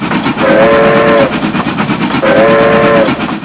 Cŕŕn Zvuk parnej lokomotivy 0:03